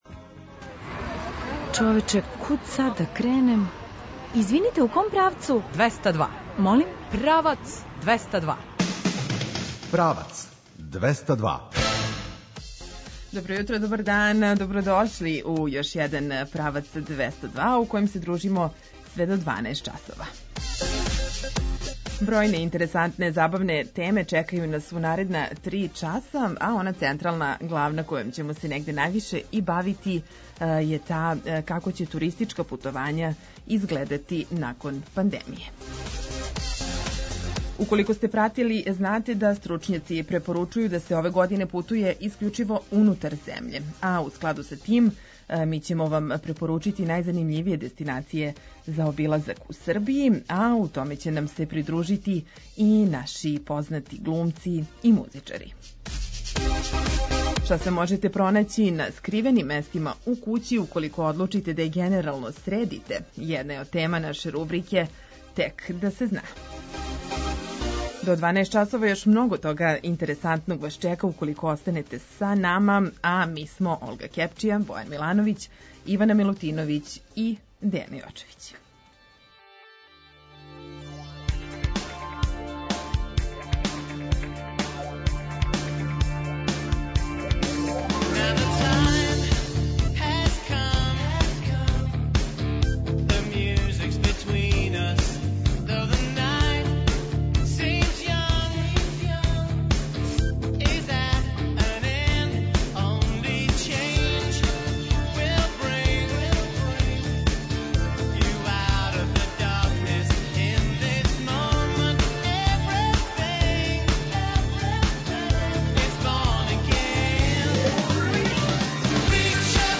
Стручњаци препоручују да се ове године путује искључиво унутар земље, а у складу са тим ћемо вам препоручити најзанимљивије дестинације за обилазак у Србији. У саветима ће нам се придружити и наши глумци и музичари.